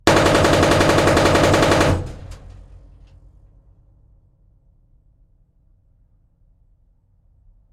На этой странице собраны звуки турели — от механизма поворота до залповой стрельбы.